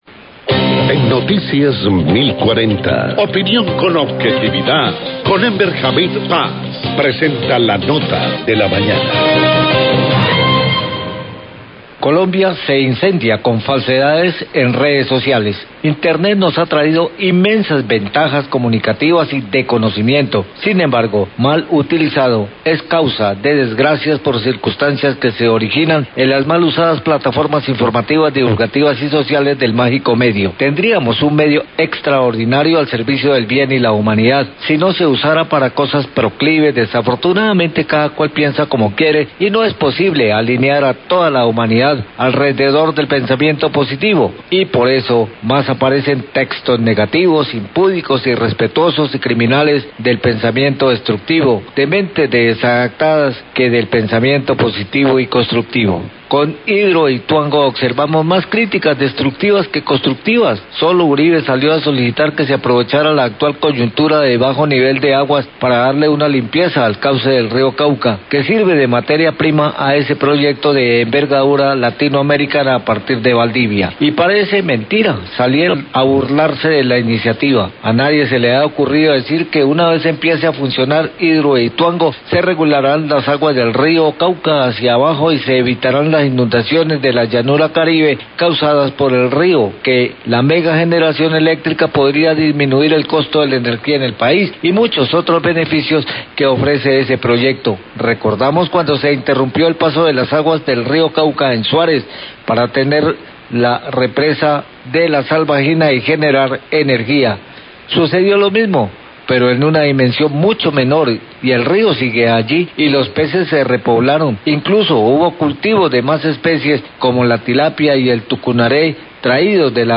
Radio
columna de opinión